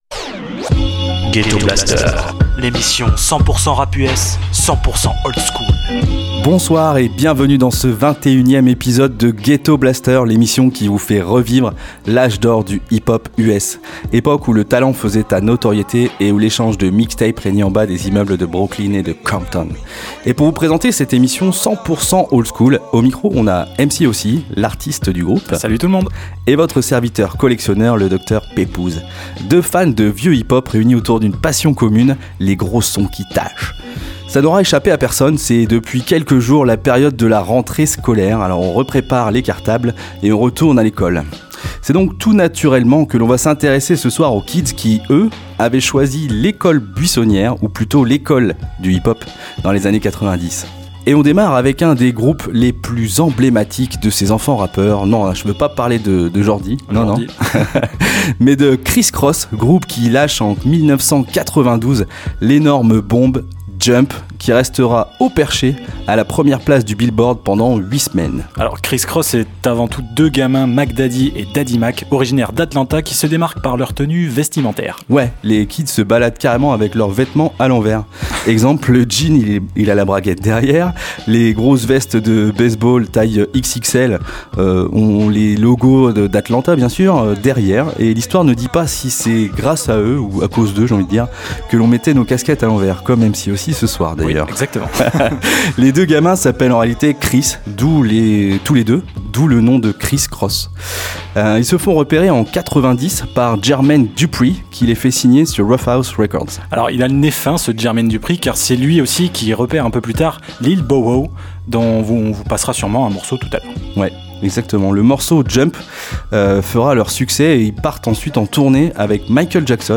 Le hip-hop US des années 80-90